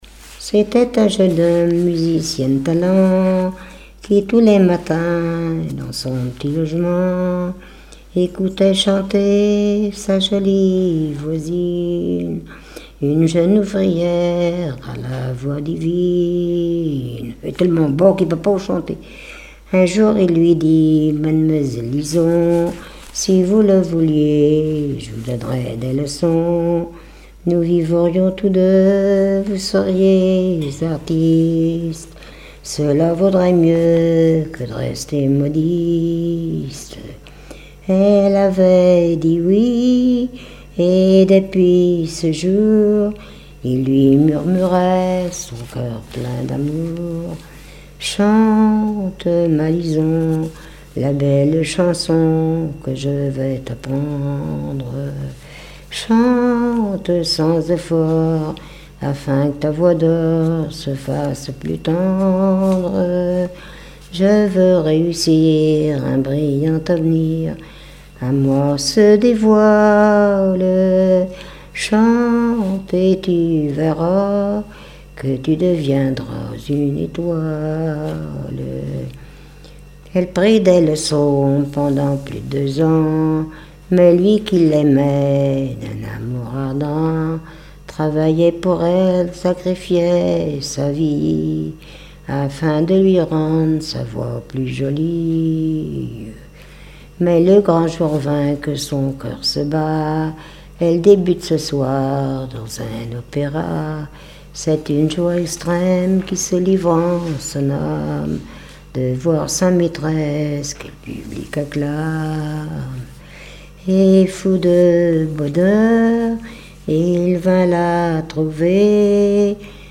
Genre strophique
Répertoire de chansons populaires et traditionnelles
Pièce musicale inédite